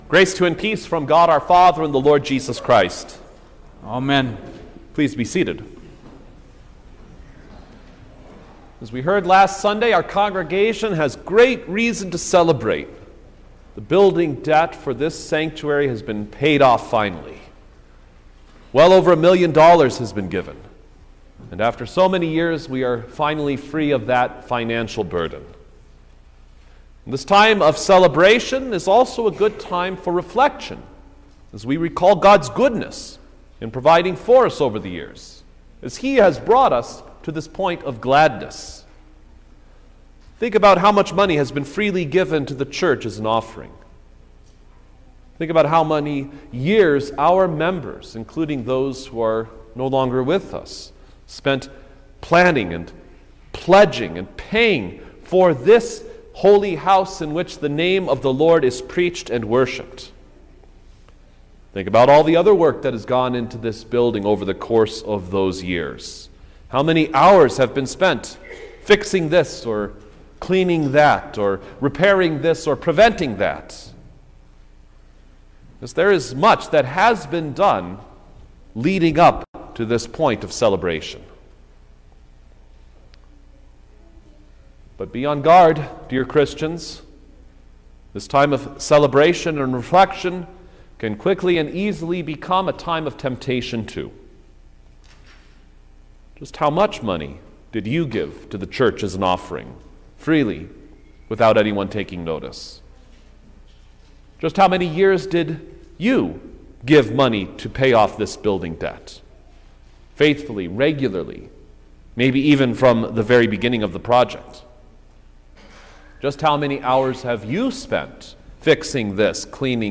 February-5_2023_Septuagesima_Sermon-Stereo.mp3